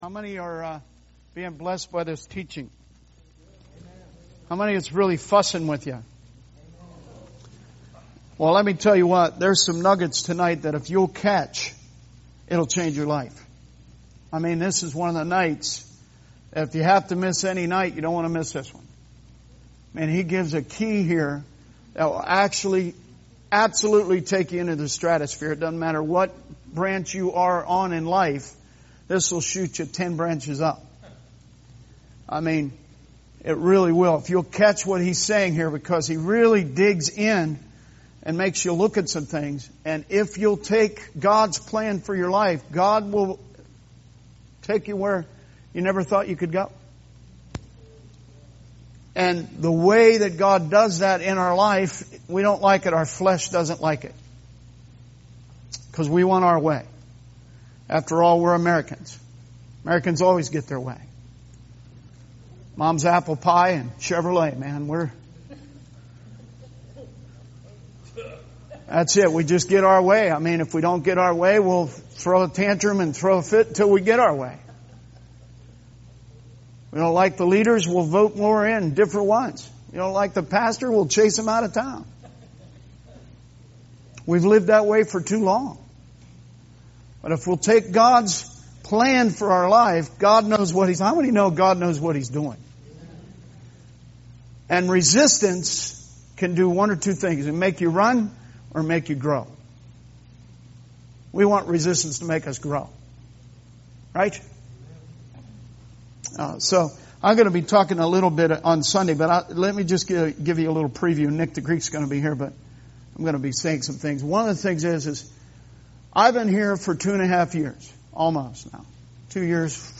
Sermon messages available online.
Bait of Satan Service Type: Wednesday Teaching Preacher